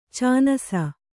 ♪ cānasa